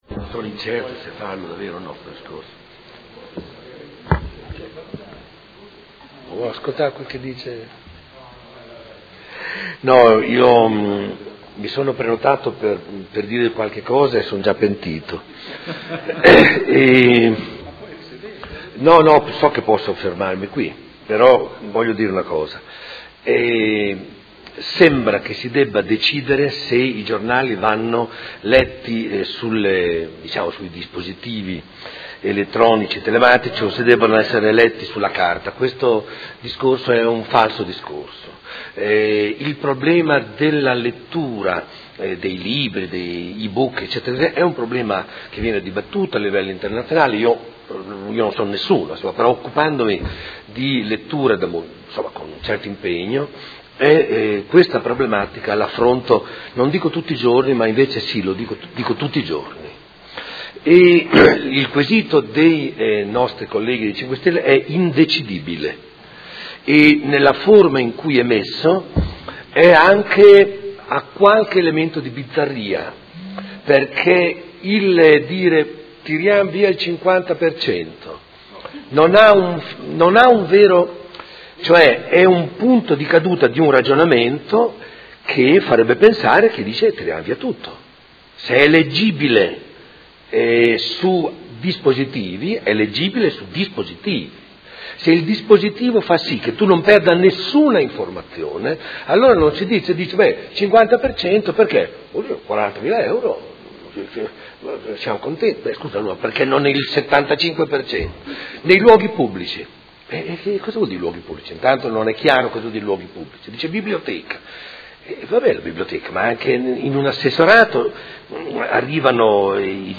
Dibattito su Mozione presentata dal Gruppo Movimento Cinque Stelle avente per oggetto: Spese quotidiani presso edicole